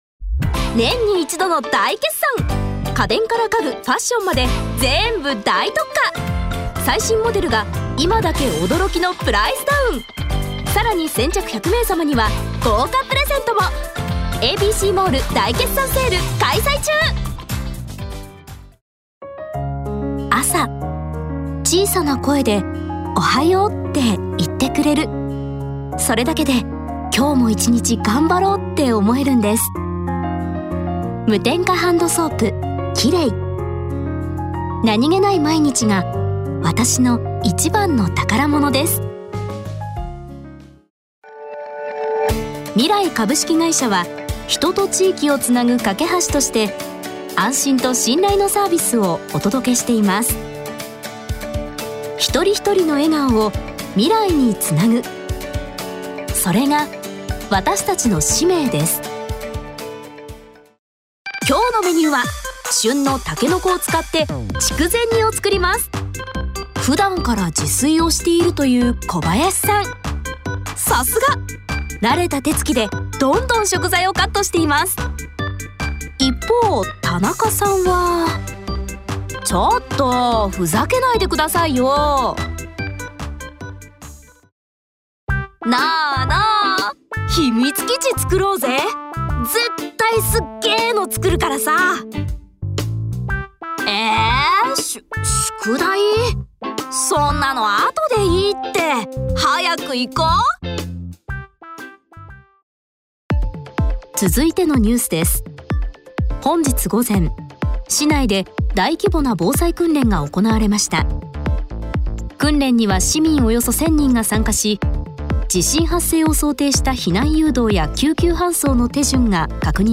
デビューしたレッスン生の、簡単な芸歴とボイスサンプルです。
ボイスサンプル